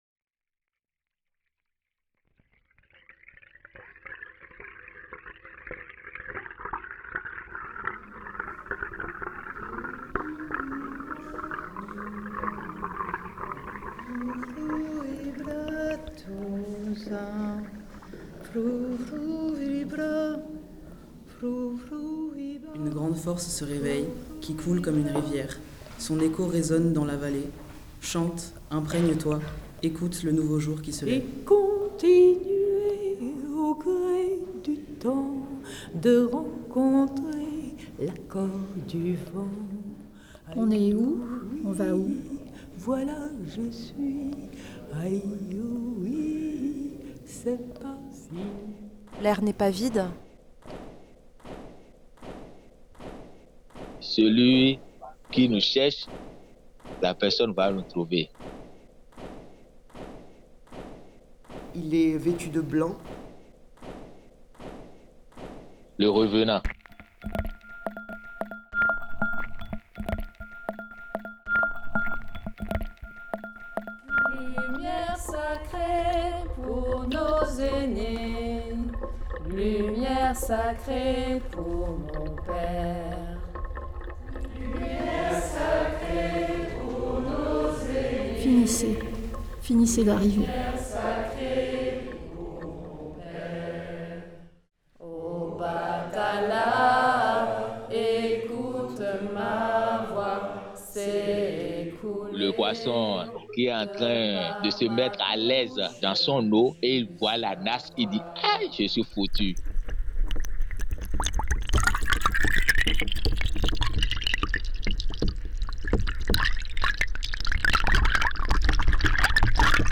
"Finissez d’arriver" propose une immersion sonore autour d’un chant Yoruba, une invitation à la communion et à l’évocation des absents. Le peuple Yoruba est un groupe ethnique d’Afrique de l’Ouest, principalement présent dans le Golfe du Bénin.